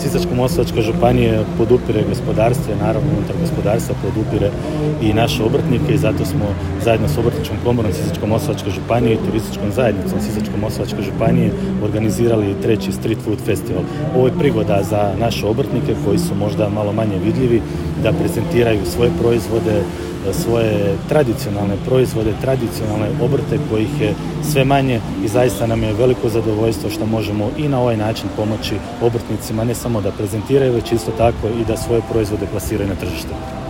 Festival i Sajam obišao je i župan Ivan Celjak koji je naglasio kako su u Sisak došli obrtnici iz cijele županije i Hrvatske prezentirati svoje proizvode i specijalitete na zadovoljstvo svih.